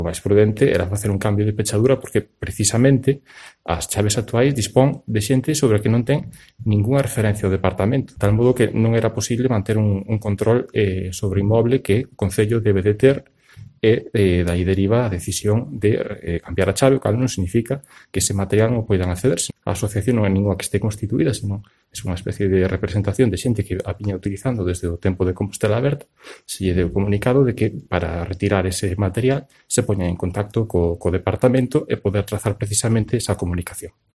El concejal Rubén Prol explica que el Concello no tenía a quién dirigirse para recuperar las llaves